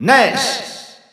Announcer pronouncing Ness's name in Spanish.
Ness_Spanish_Announcer_SSBU.wav